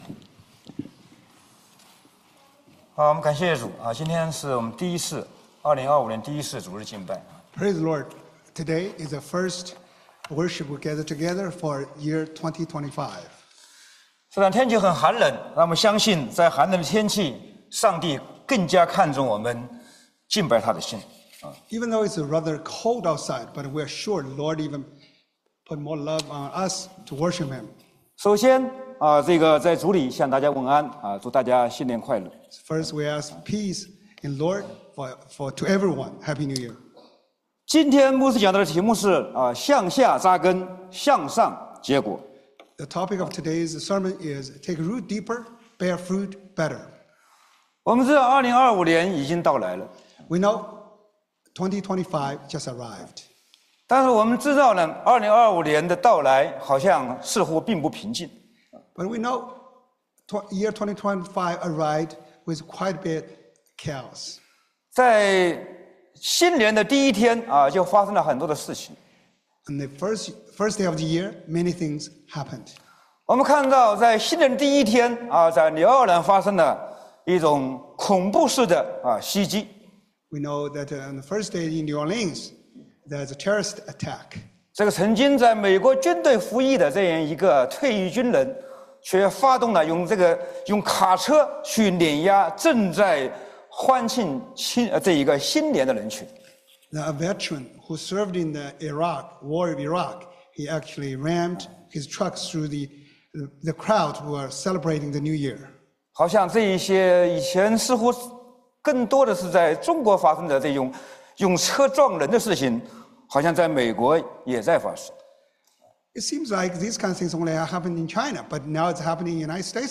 Sermons – Chinese Christian Church of Baltimore